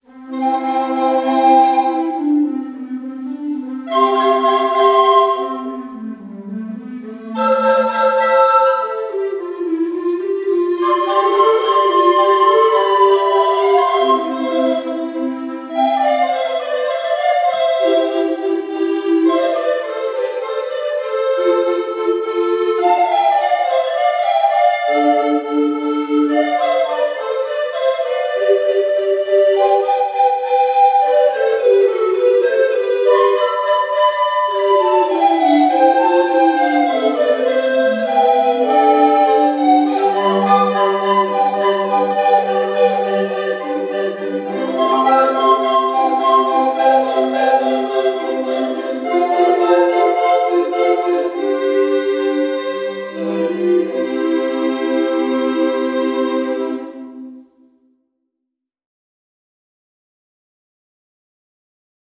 „The Fairy Queen“ von Purcell, als Blockflötenquartett bearbeitet, lag plötzlich in meinen Händen.
an einem recht heißen Samstag unsere Blockflötenquartette für das Klassenspiel und auch die Fairy Queen professionell aufnehmen zu lassen.
08-Prelude.wav